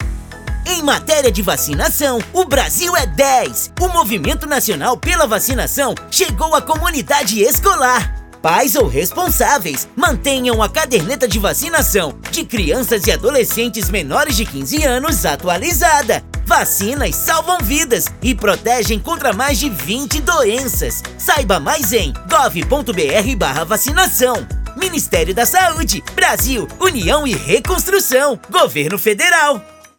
Áudio - Spot 30seg - Vacinação nas escolas - Ministério da Saúde - 1,1mb .mp3